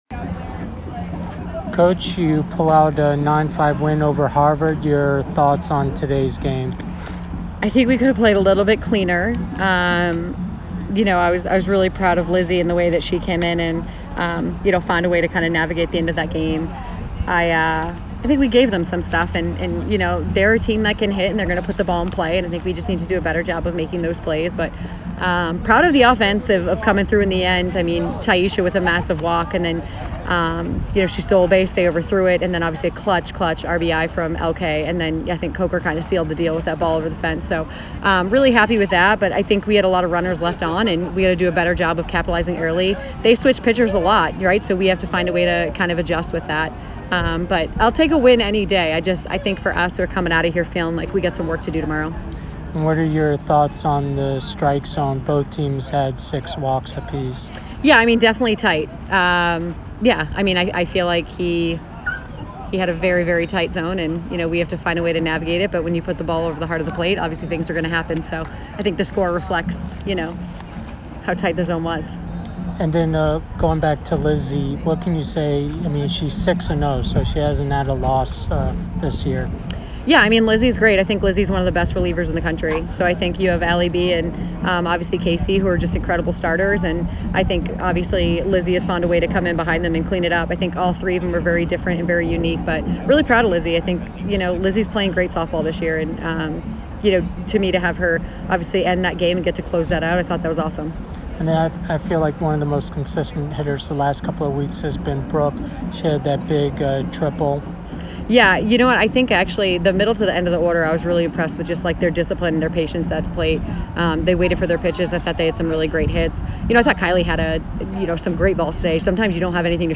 Harvard Postgame Interview